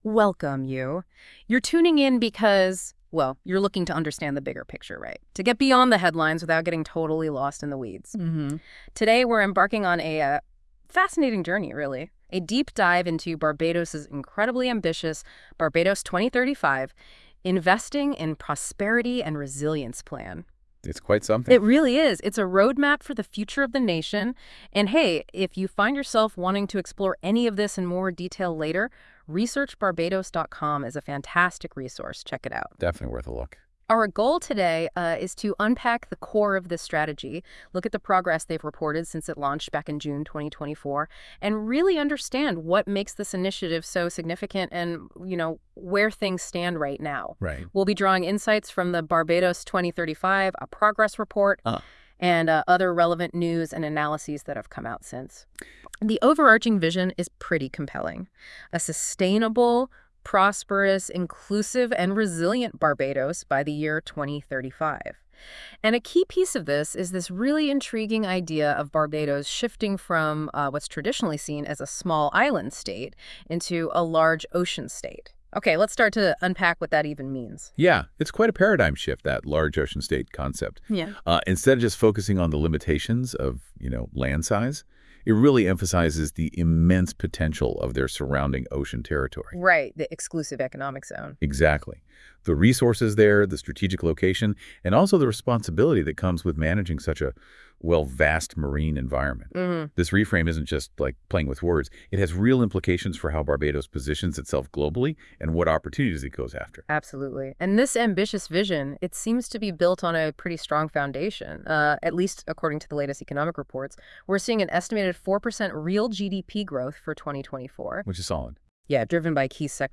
Expert commentary on Barbados' strategies for prosperity and resilience